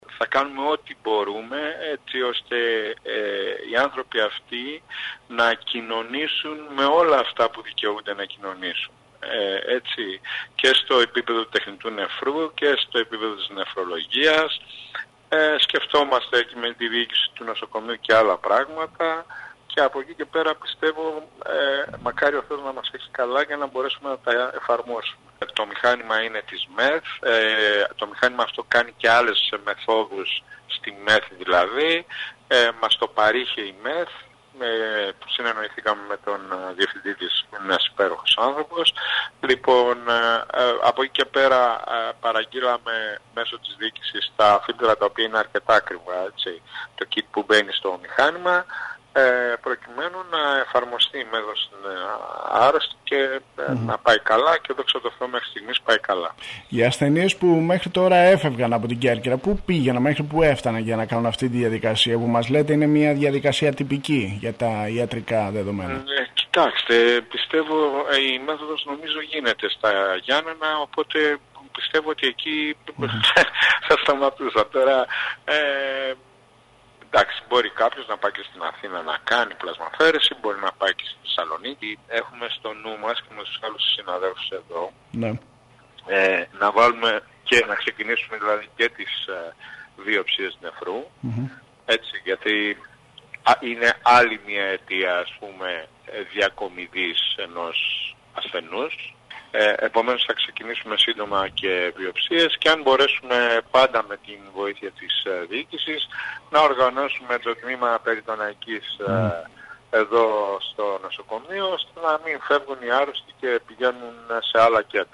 μιλώντας στο σταθμό μας.